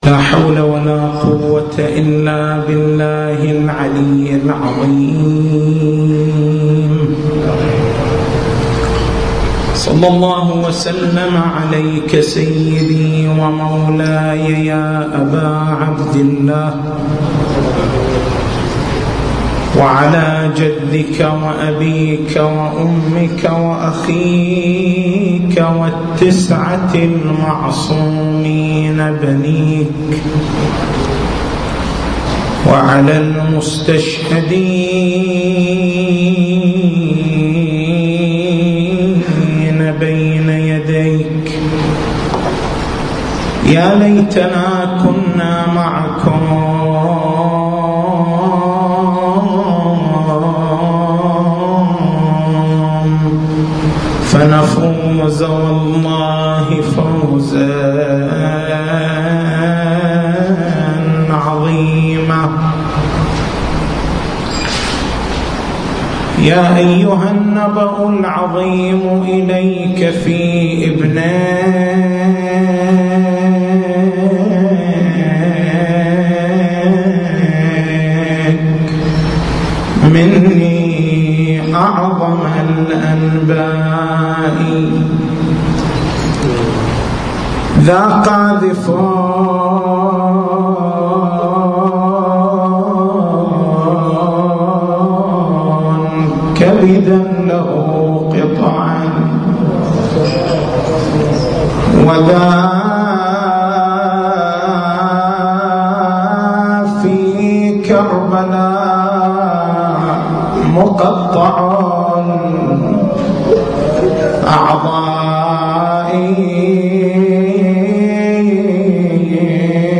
تاريخ المحاضرة: 06/09/1434 نقاط البحث: سند الحديث هل ورد هذا الحديث من طرق الشيعة؟